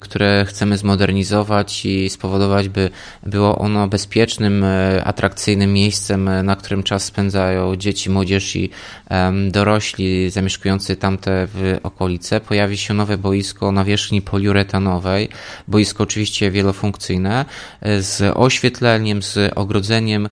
– U zbiegu Mickiewicza i Wawelskiej znajduje się stare, osiedlowe boisko, mówi Tomasz Andrukiewicz, prezydent Ełku.